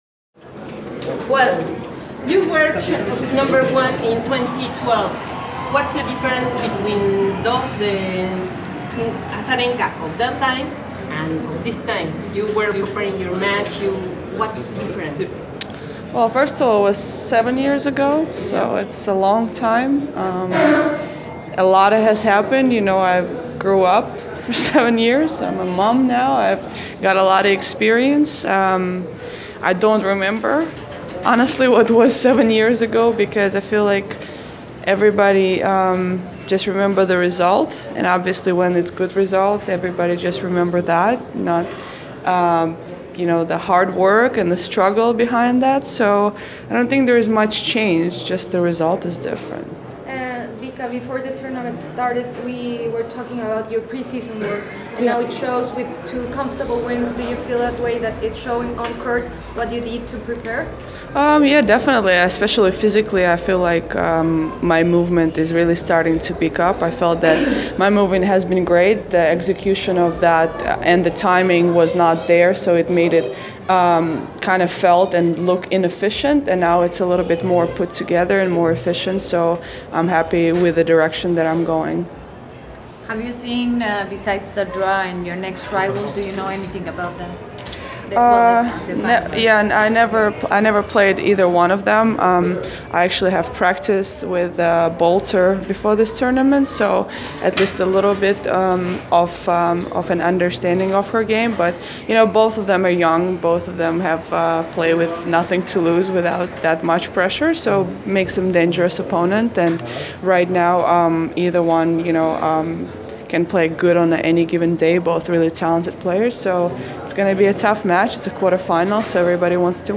Press Conference – Victoria Azarenka (27/02/2019)